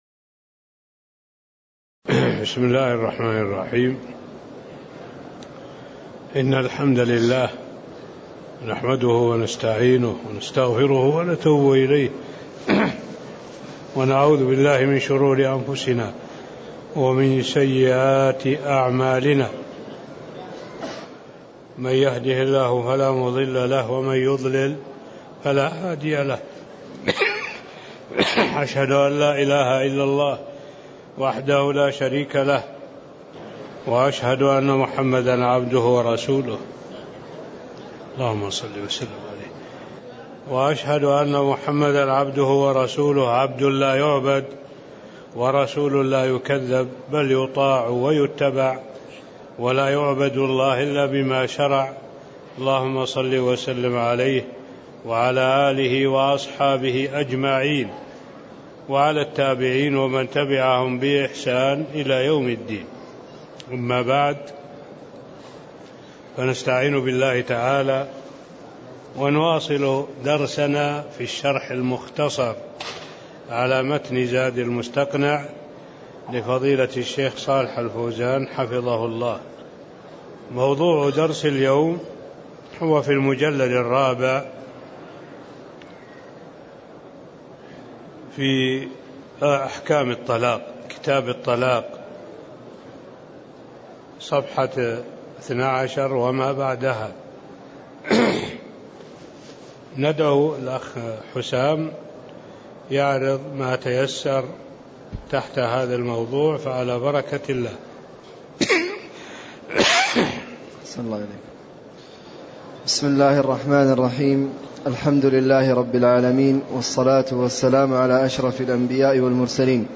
تاريخ النشر ١٩ رجب ١٤٣٥ هـ المكان: المسجد النبوي الشيخ: معالي الشيخ الدكتور صالح بن عبد الله العبود معالي الشيخ الدكتور صالح بن عبد الله العبود فصل في بيان الطلاق السنّي والبدعي (01)